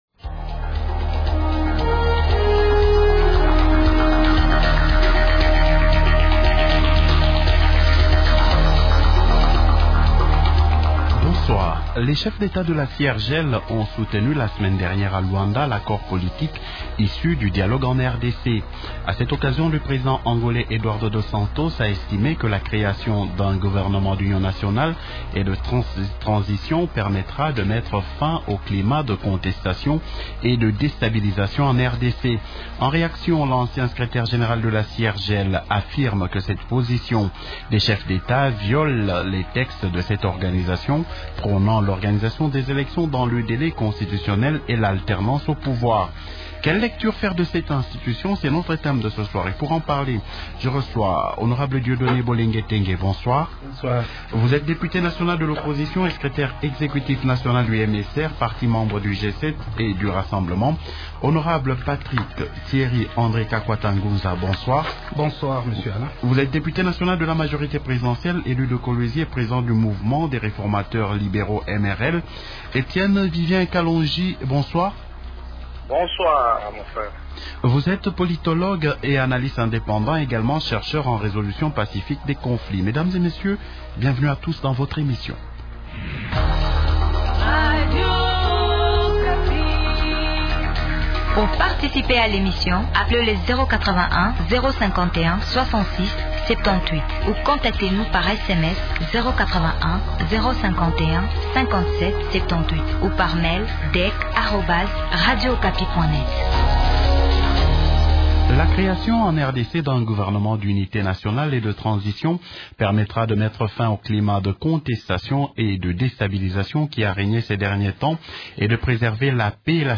-Patrick-Thierry-André Kakwata Nguza, Député national de la majorité présidentielle, élu de Kolwezi et Président du Mouvement des réformateurs libéraux ( Mrl). -Dieudonné Bolengetenge, Député national de l’opposition et secrétaire exécutif national du MSR, parti membre du G7 et du Rassemblement.